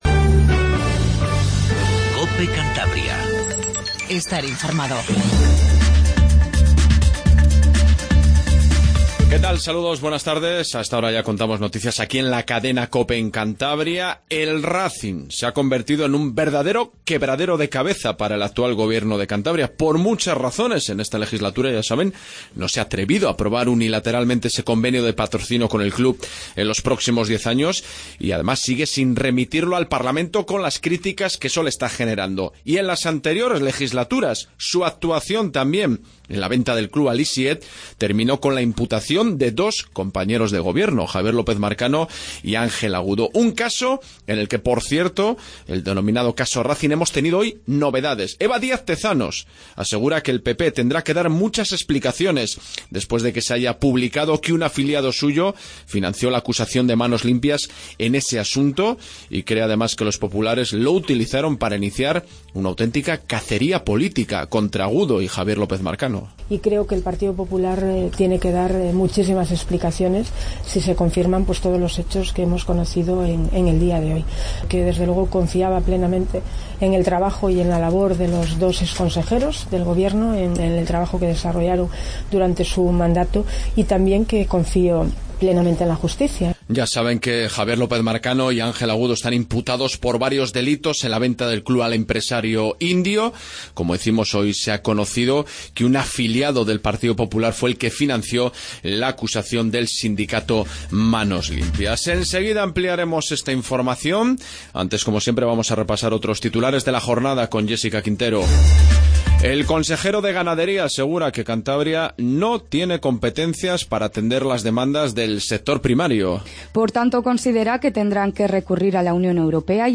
INFORMATIVO REGIONAL 14:10